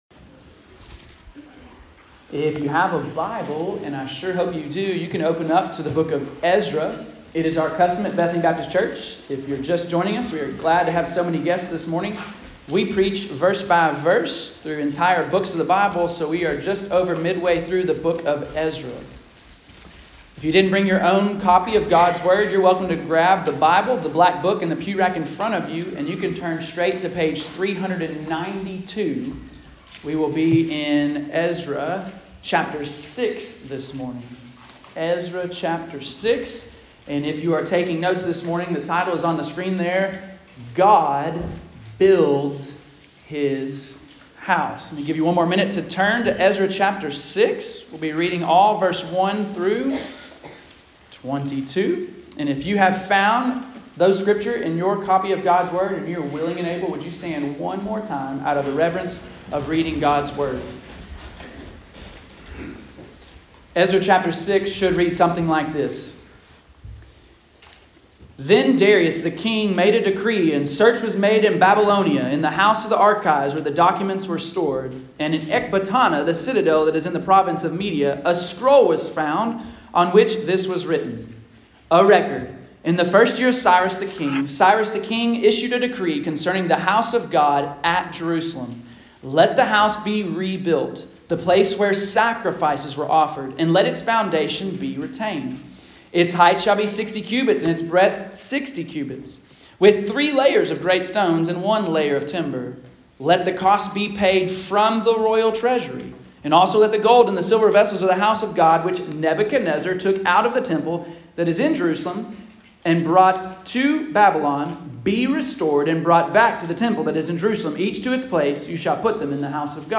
Bethany Baptist Church Listen to Sermons